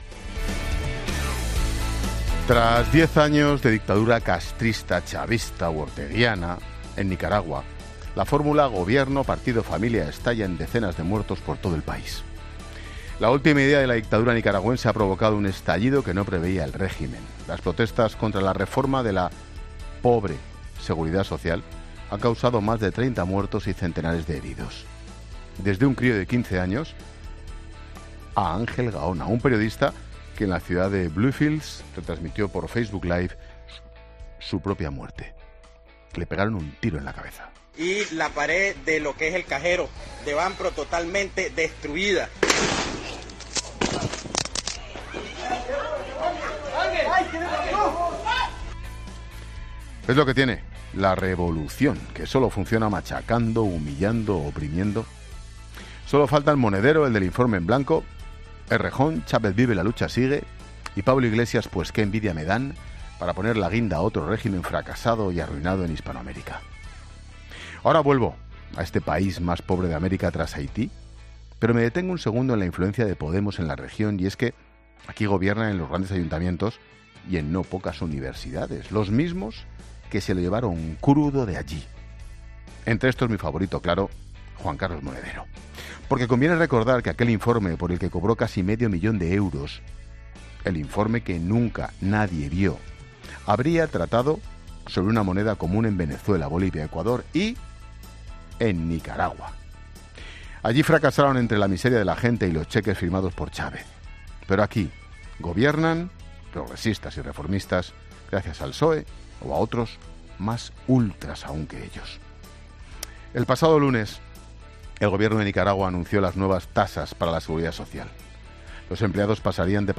Monólogo de Expósito
El comentario de Ángel Expósito tras las protestas en Nicaragua, con víctimas mortales.